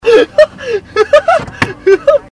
Laugh 4